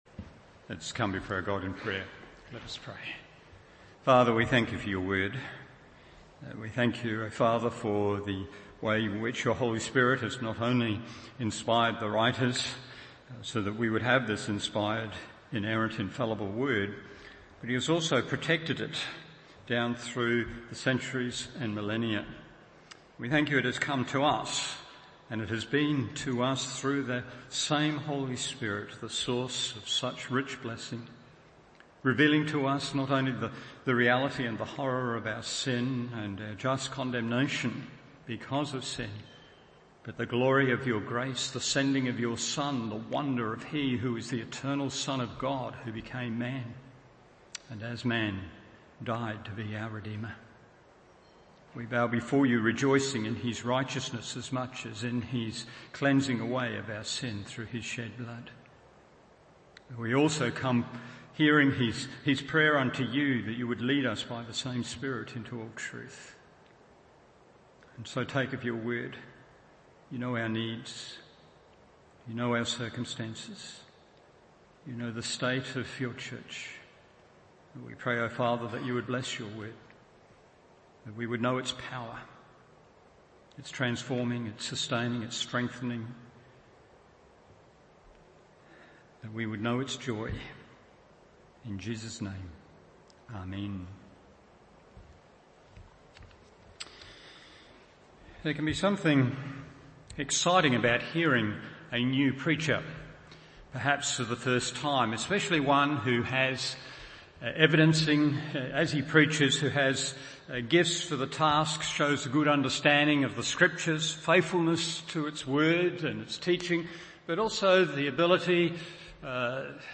Evening Service Acts 18:24-28 1. A Ministry of Encouragement 2. A Ministry of Enrichment 3. A Ministry of Extension…